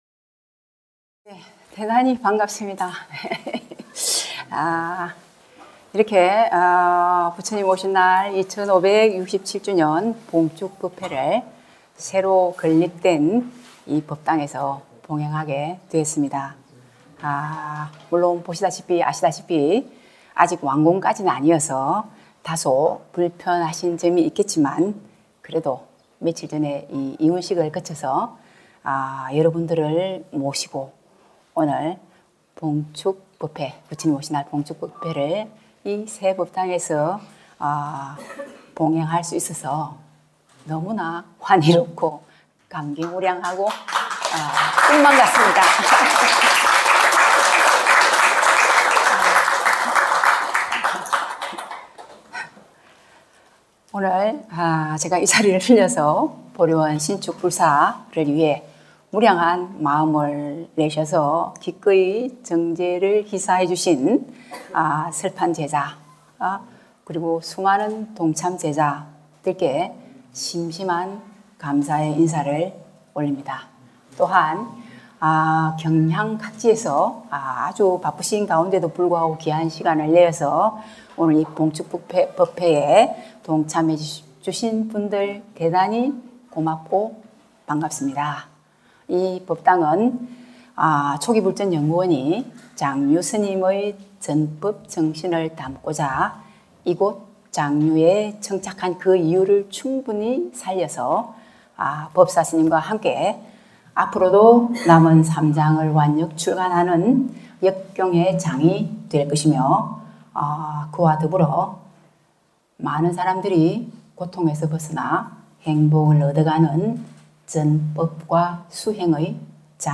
불기 2567년 부처님 오신날 봉축 법회 원장 스님 인사말씀